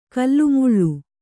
♪ kallumuḷḷu